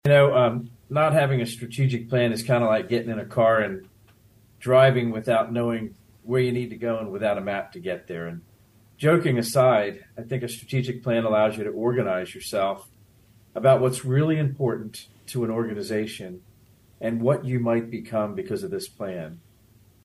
Kansas State University president Richard Linton spoke about the plan on Thursday and said it was necessary in helping the university move into the future.